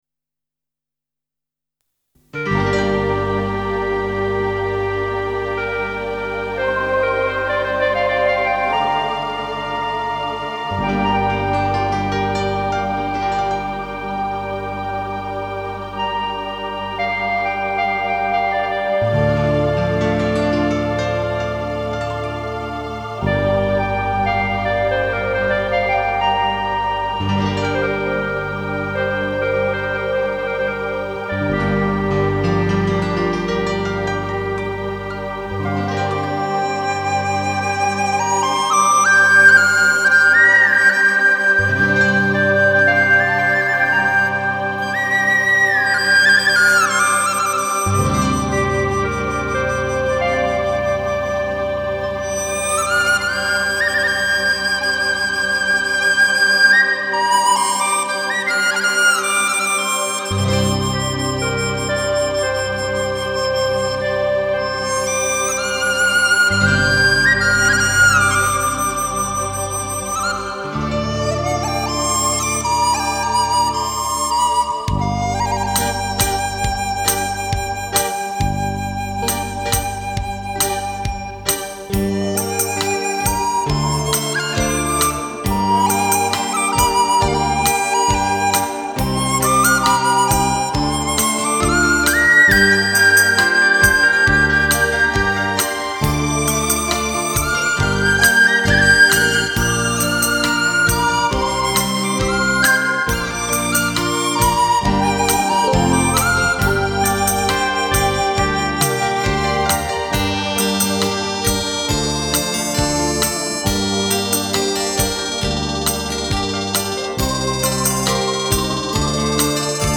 展现民乐历久弥新的特质，各音乐元素迸发无限可能，来自音乐王国的新声遗音，抚慰心灵的自然性灵佳韵。
笛子
美丽的心情始于一段带着朝露与阳光的笛声和那草原上吹过的一阵悠然的风